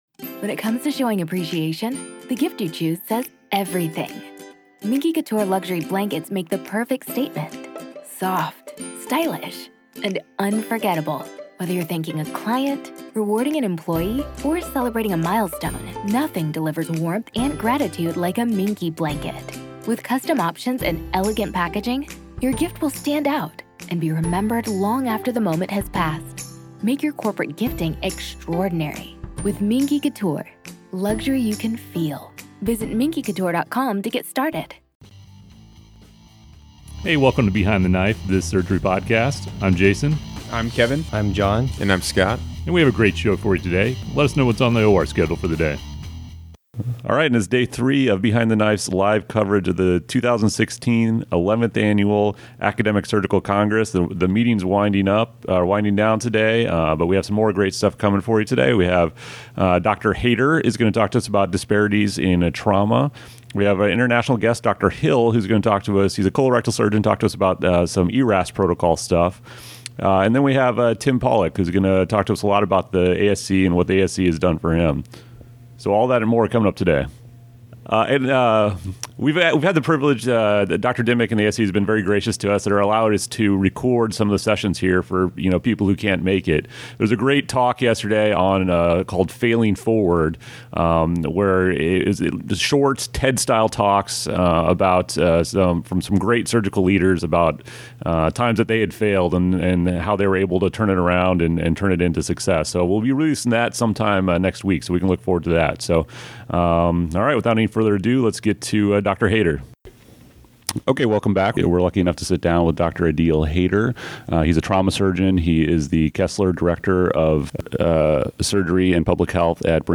Facebook Twitter Headliner Embed Embed Code See more options During our third and final day at the ASC we once again had some great interviews.